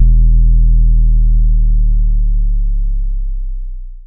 808 (8) Sizzle.wav